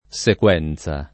sequenza [ S ek U$ n Z a ]